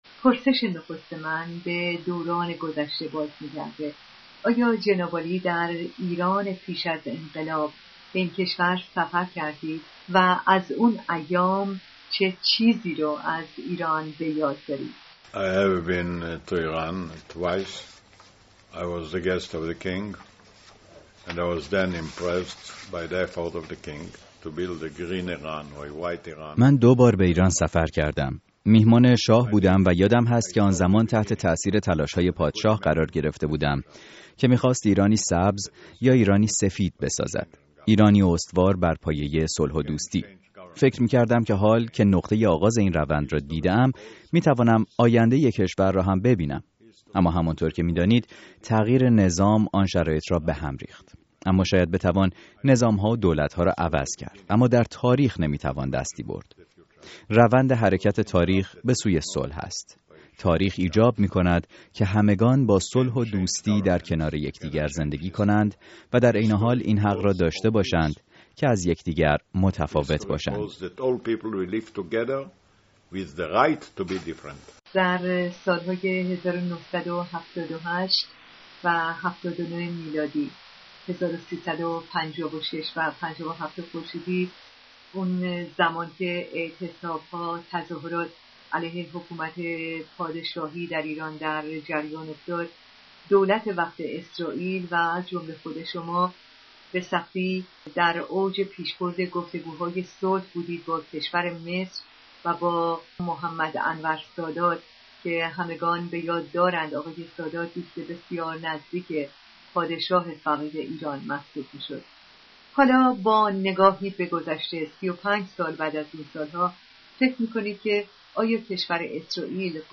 شیمون پرز، رییس جمهوری اسرائیل، روز پنج‌شنبه، در گفت‌وگوی اختصاصی* با رادیو فردا به مناسبت آغاز سال نو ایرانی به پرسش ها در خصوص سیاست کشورش در قبال ایران پاسخ داده است.